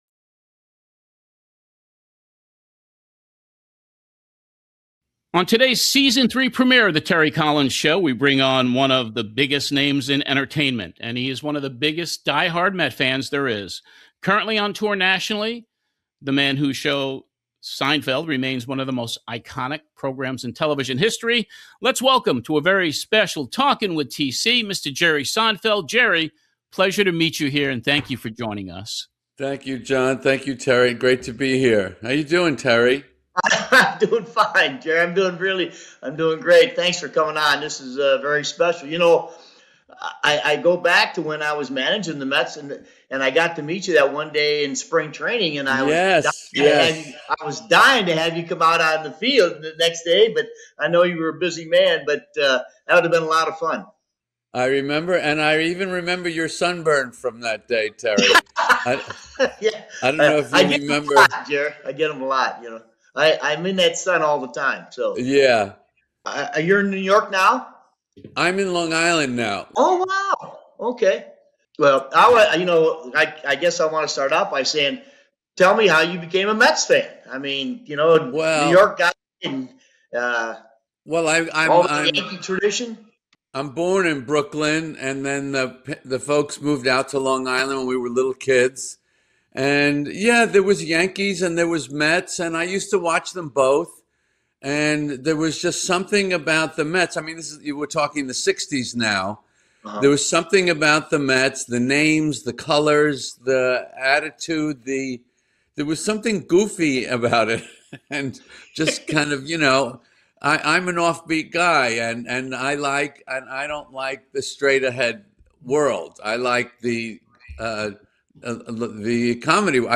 Here is the complete interview from The Terry Collins Show with the one and only JERRY SEINFELD! Jerry and Terry talk baseball, from Tommy Agee and the 69 Mets to Juan Soto and the 2026 team.